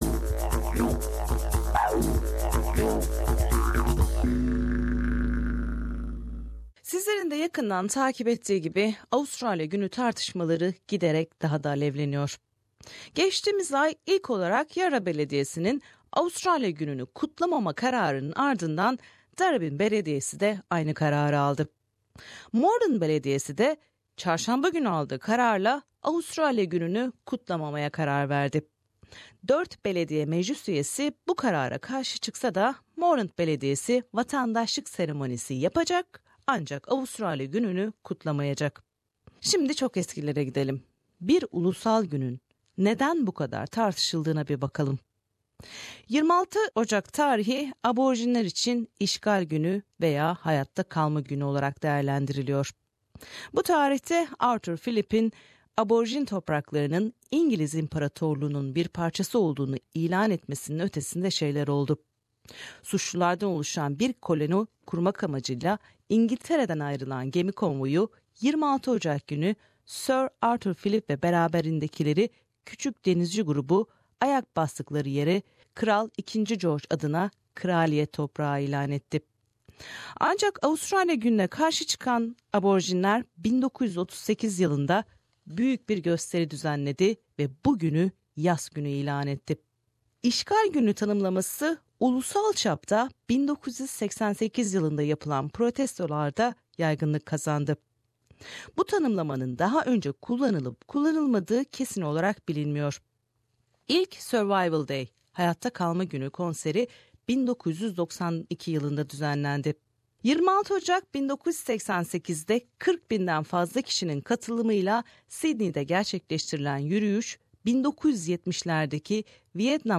Melbourne'daki Moreland Beldiyesi de Avustralya Günü'nü kutlamama kararı aldı. Kararın alınmasında öncülük eden Socialist Alliance Grubu'ndan Belediye Meclis üyesi Sue Bolton ve bu karara karşı çıkan Belediye Meclis üyesi Öztürk Yıldız ile yaptığımız söyleşiler.